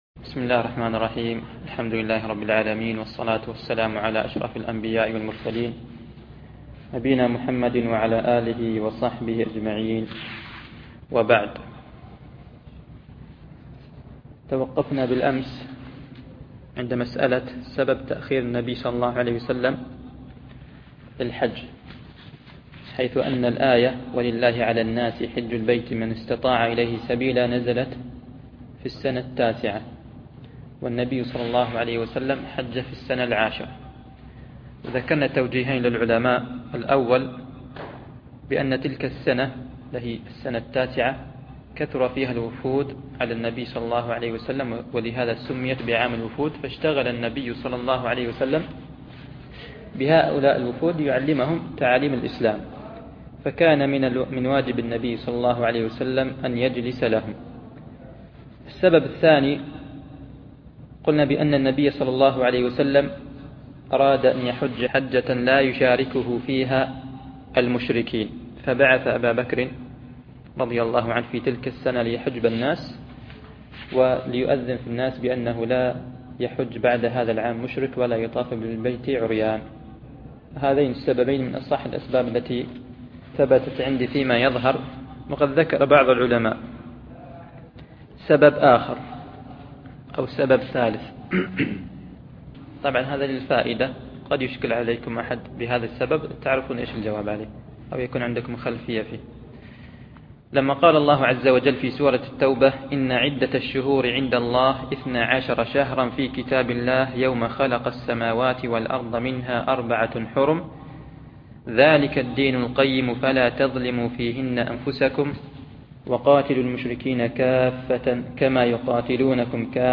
شرح كتاب الحج من منهج السالكين - الدرس الثاني